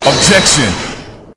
objection.wav